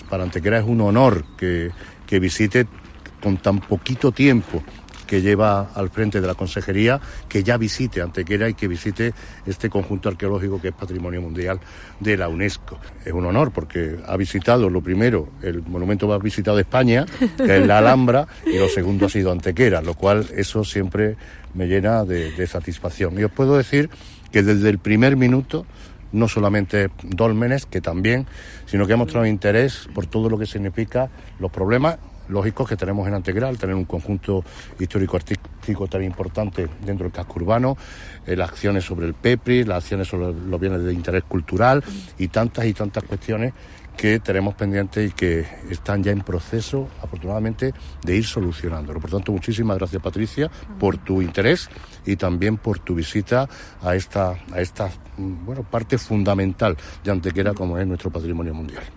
Durante las declaraciones realizadas en la puerta del dolmen de Menga, el alcalde Manolo Barón ha agradecido la predisposición e interés que la consejera Patricia del Pozo ha mostrado a la hora de venir a Antequera e interesarse tanto por todo lo que supone el Conjunto Arqueológico de los Dólmenes y su futuro Museo como por la muestra que Cristóbal Toral tiene en la actualidad en el MVCA.
Cortes de voz